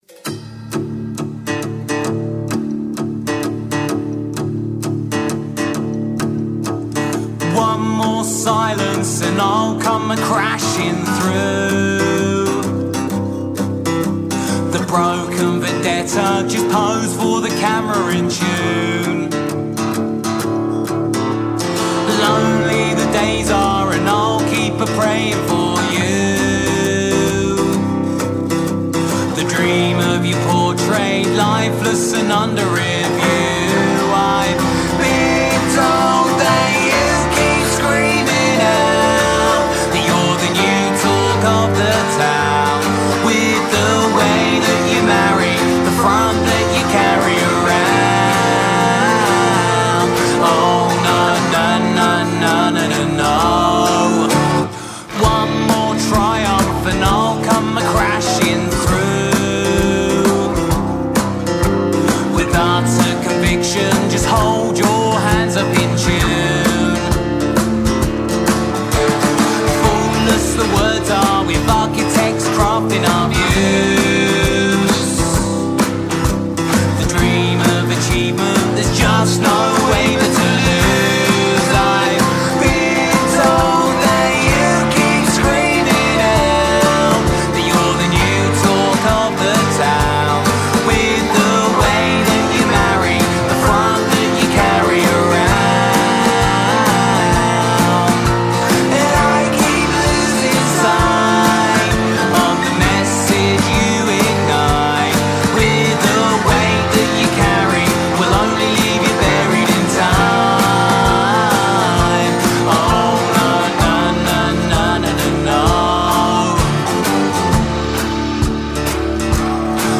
indie-pop
Influenced by the best of British indie music
up-tempo lyrically driven songs
drums